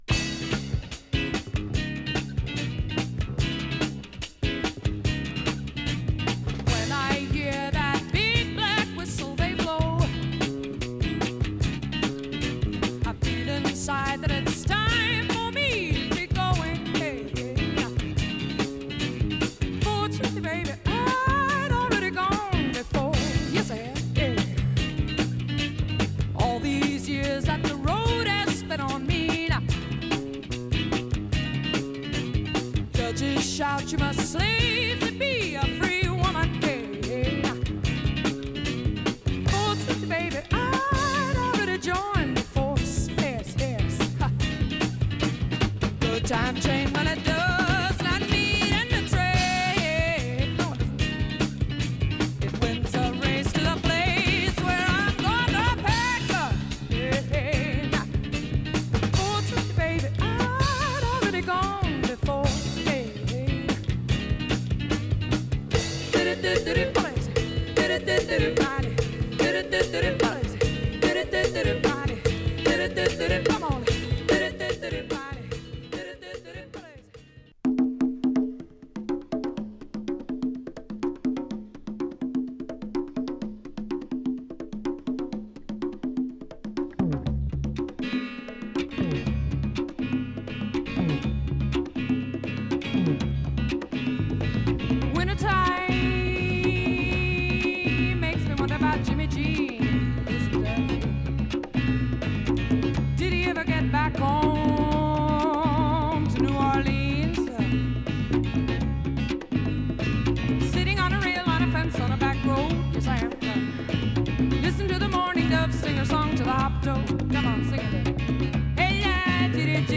小気味よいカッティングが冴える
スリリングなパーカッションとギターにボーカルが絡み合う